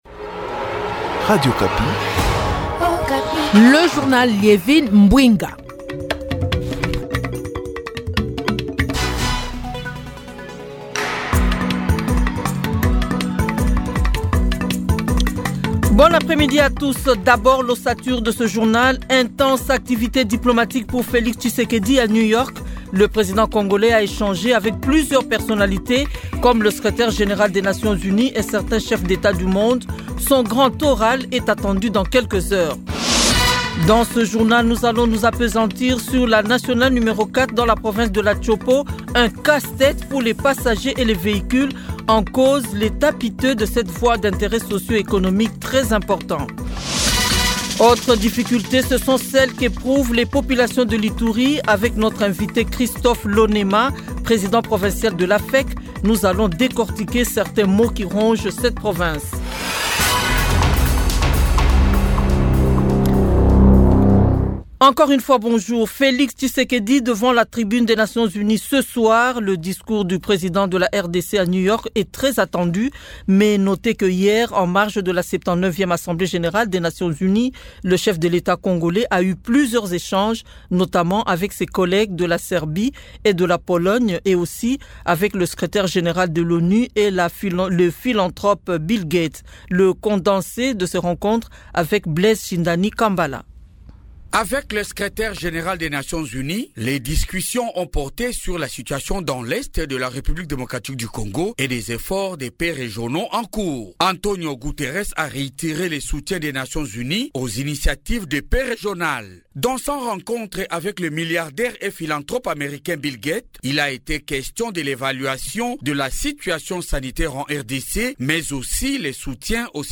Journal Francais 15 heures